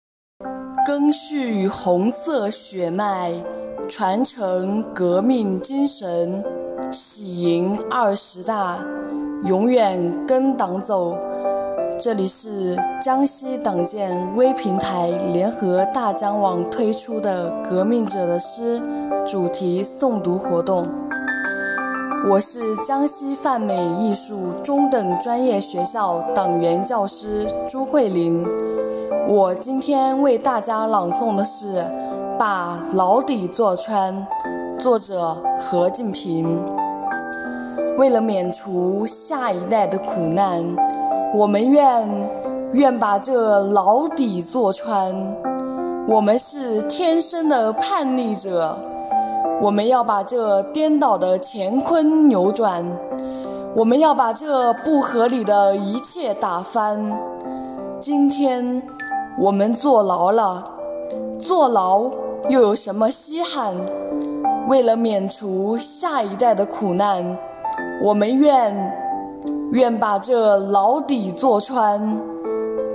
即日起，江西泛美艺术中等专业学校微信公众号开设〔革命者的诗主题诵读〕专栏，通过视频或音频的形式，刊发我校党员教师的诵读作品，今天推出第三期：何敬平《把牢底坐穿》。